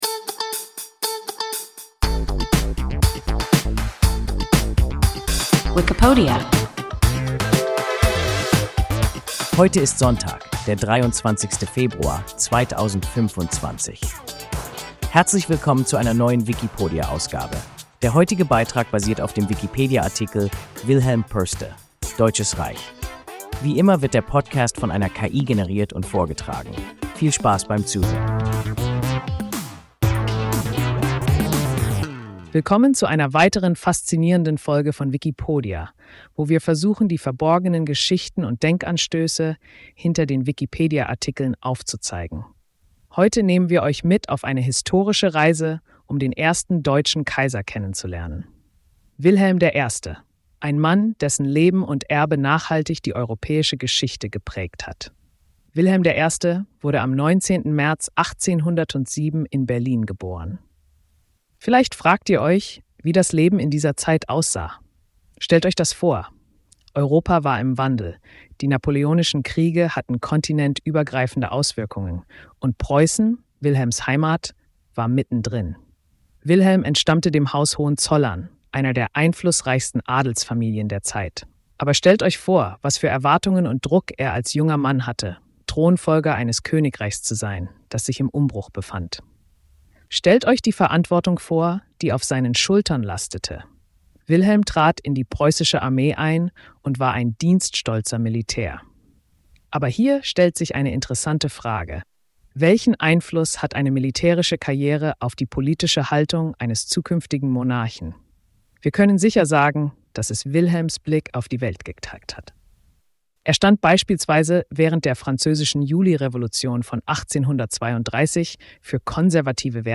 Wilhelm I. (Deutsches Reich) – WIKIPODIA – ein KI Podcast